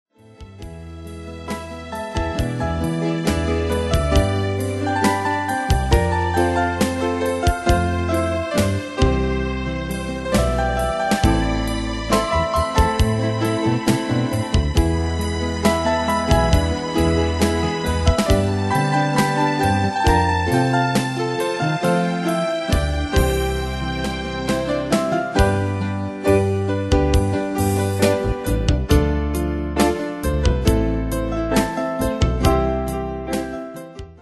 Style: Rock Année/Year: 1973 Tempo: 66 Durée/Time: 4.42
Danse/Dance: SlowRock Cat Id.
Pro Backing Tracks